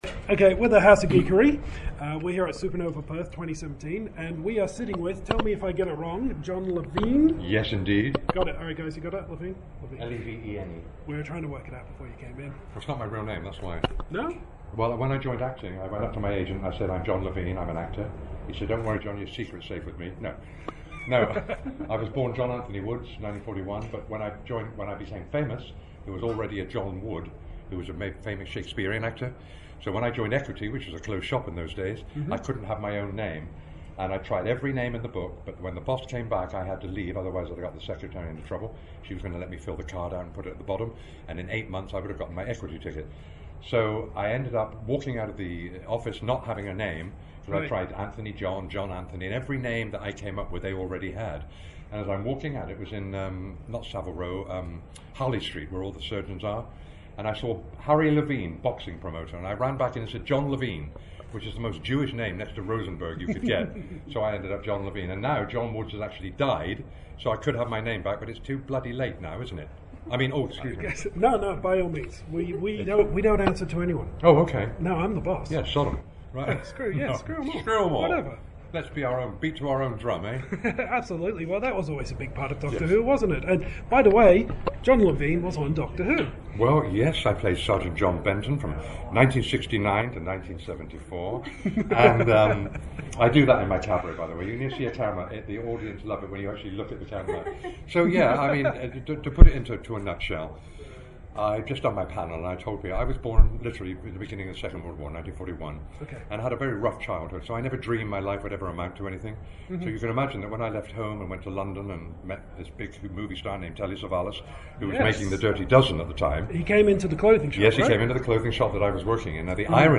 Exclusive Interview with Doctor Who’s John Levene!
Back to Supanova, and this time we’re sitting down with John Levene, best known as Sgt. John Benton in the original run of Doctor Who.
Try and listen to the audio if you can, because the transcript can’t capture his patter.